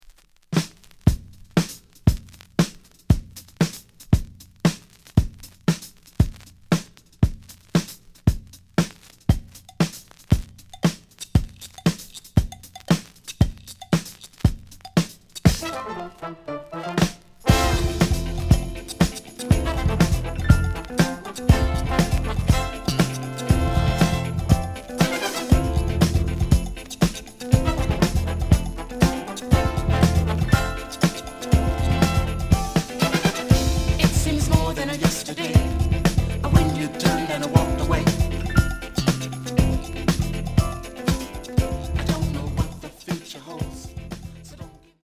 The audio sample is recorded from the actual item.
●Genre: Soul, 80's / 90's Soul
Slight noise on both sides.